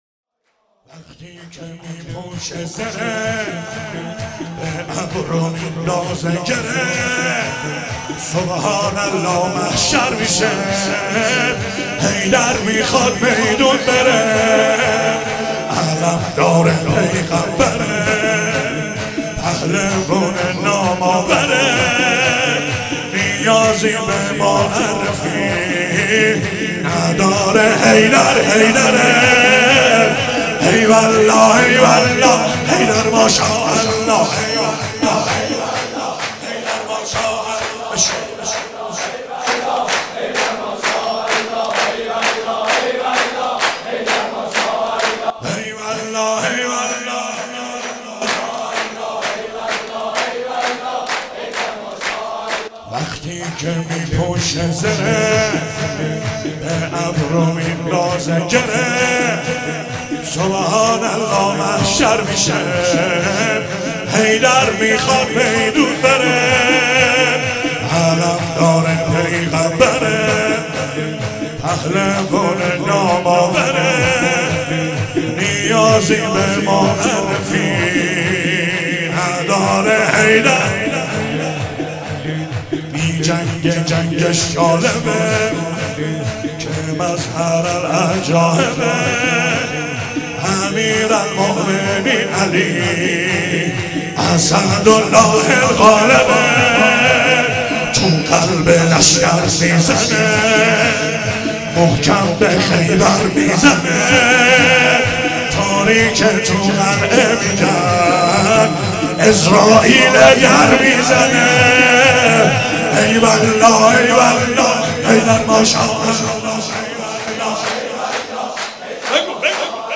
مراسم هفتگی 26 اسفند 95
چهاراه شهید شیرودی حسینیه حضرت زینب (سلام الله علیها)
شور